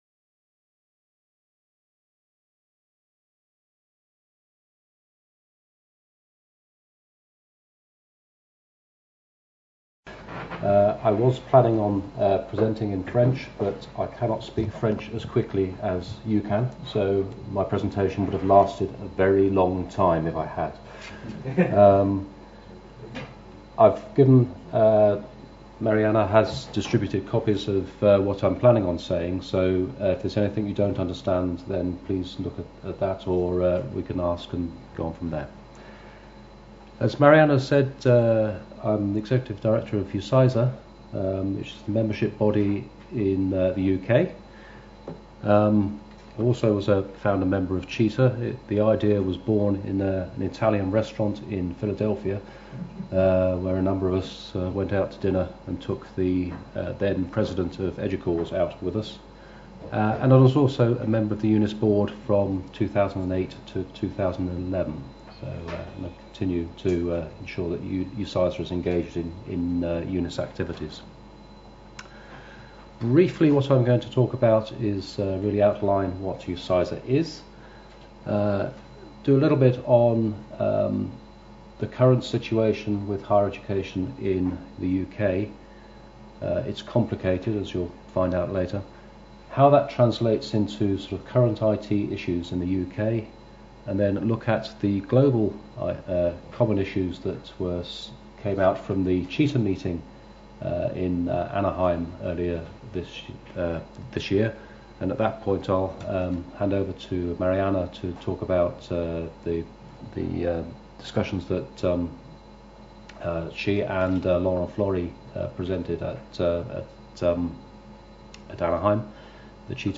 Restitution de la mission à EDUCAUSE organisée par l’AMUE le 8 novembre 2013 à la maison des universités. Les participants de la délégation française partagent les principaux sujets abordés à EDUCAUSE 2013 et les bonnes pratiques identifiées à l’occasion de pré-visites aux universités américaines.